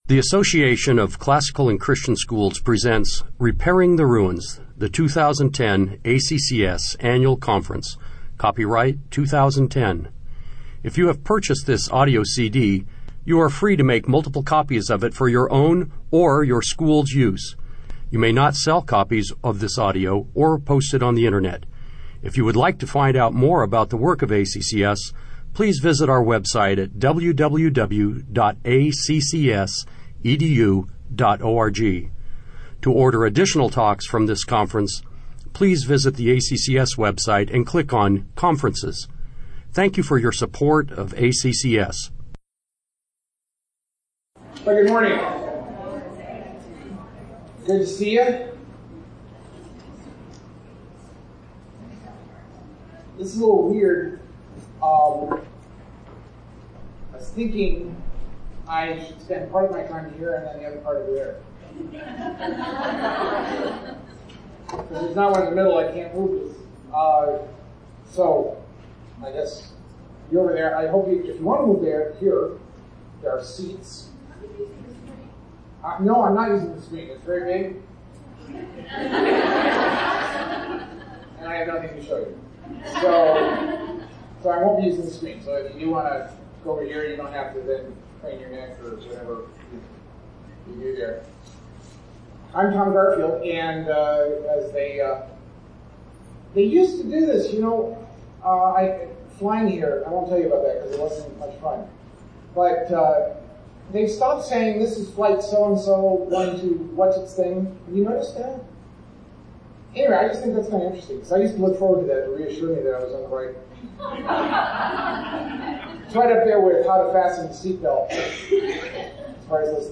2010 Workshop Talk | 0:50:50 | All Grade Levels, Virtue, Character, Discipline